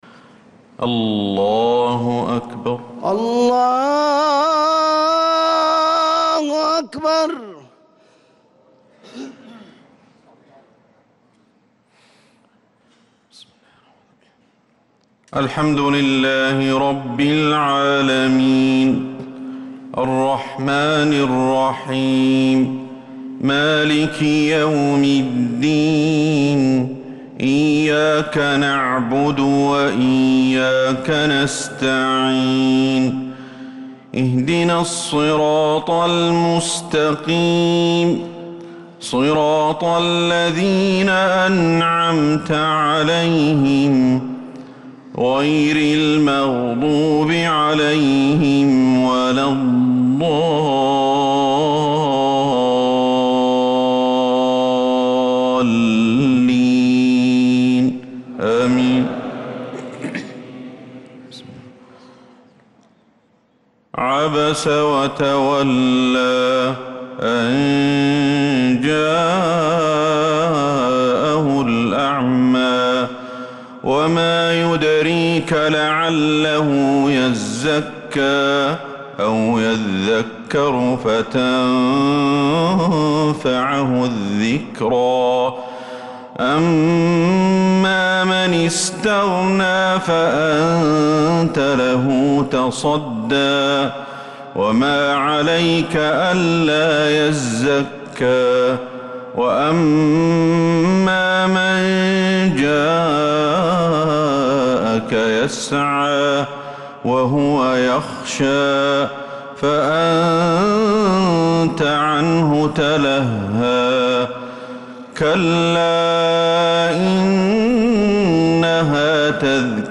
مغرب السبت 4-7-1446هـ سورة عبس كاملة | Maghrib prayer from Surat Abasa 4-1-2025 > 1446 🕌 > الفروض - تلاوات الحرمين